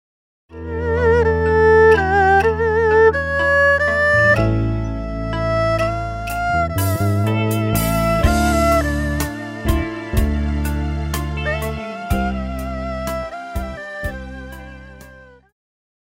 爵士
套鼓(架子鼓)
乐团
演奏曲
融合爵士
独奏与伴奏
有节拍器
二胡诠释了
那些失落与无奈的感受
Drum N’Bass的对话